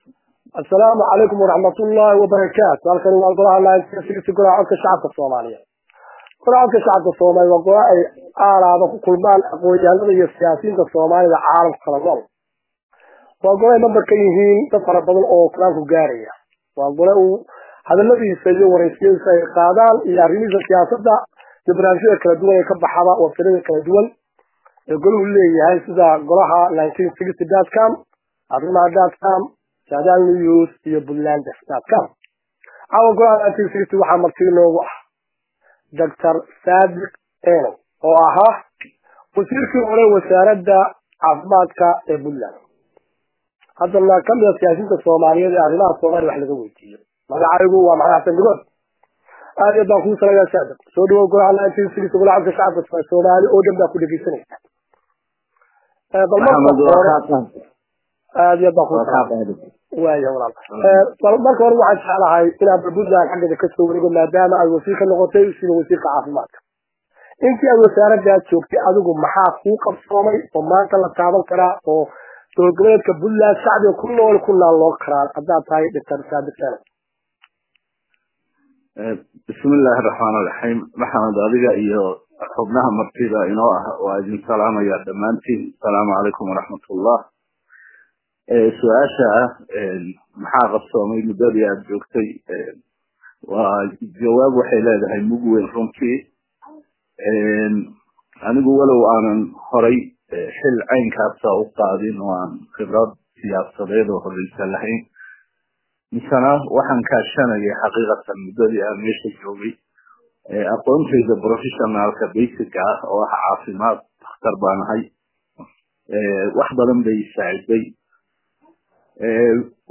Waraysi dhinacyo badan taabanaya siiyey golaha1960